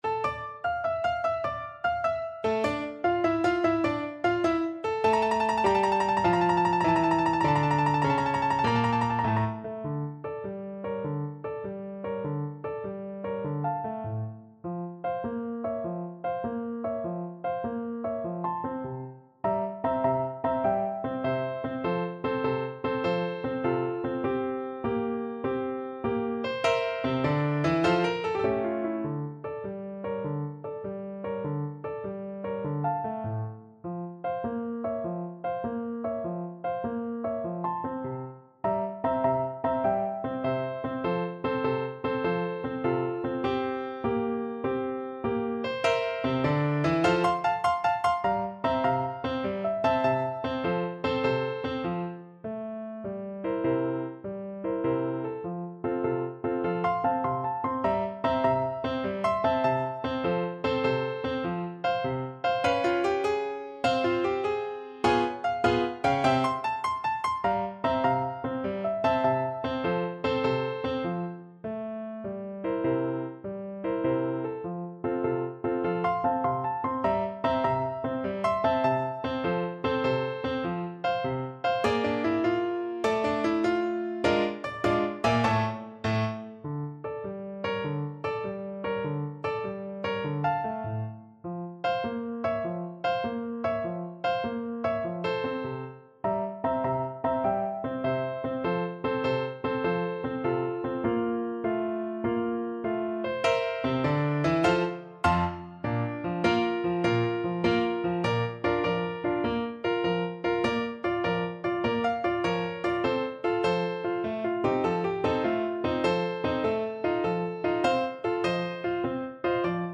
6/8 (View more 6/8 Music)
Allegro moderato . = c.100 (View more music marked Allegro)